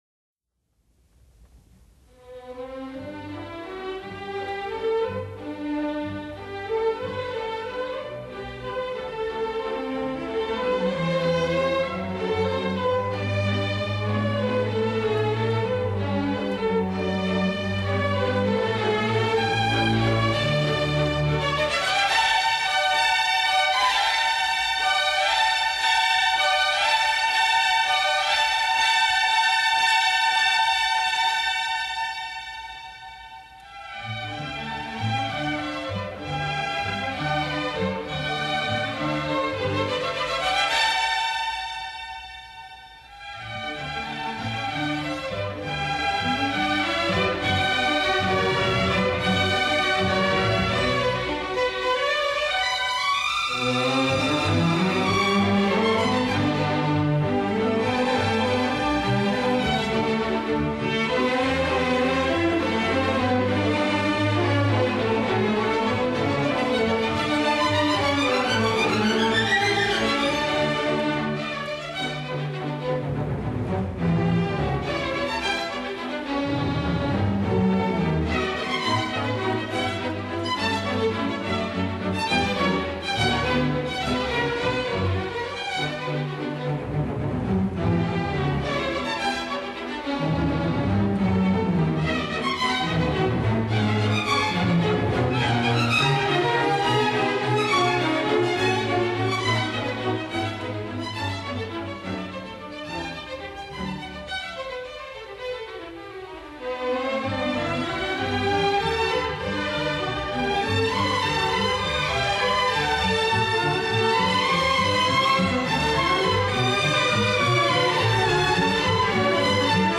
【所属类别】音乐 古典